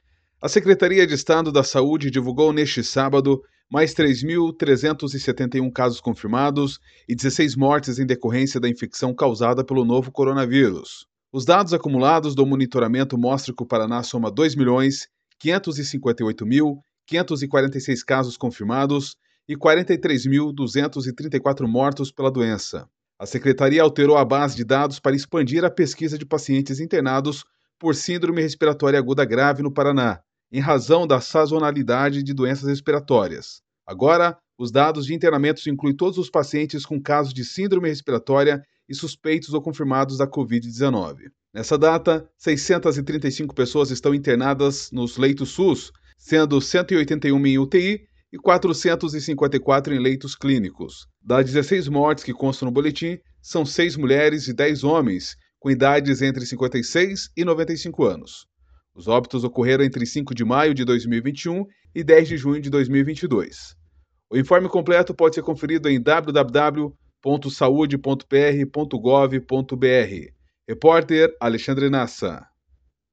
BOLETIM COVID_1.mp3